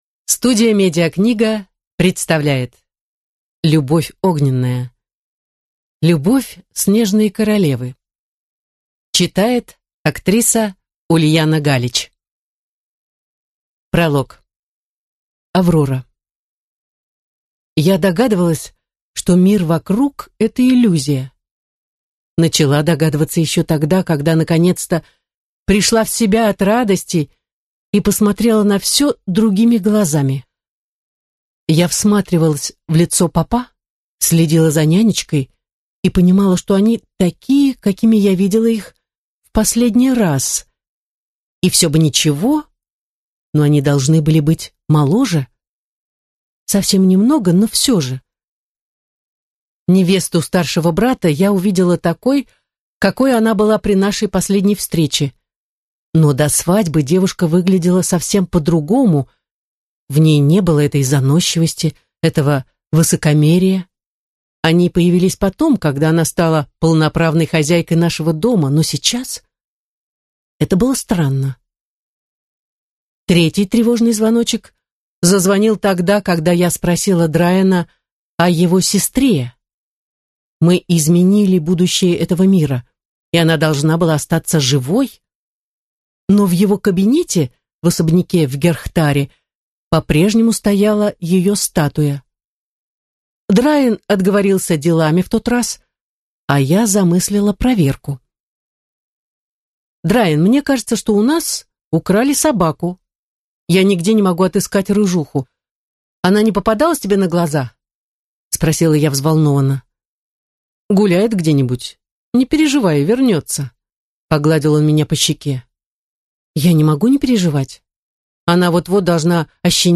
Аудиокнига Любовь Снежной Королевы | Библиотека аудиокниг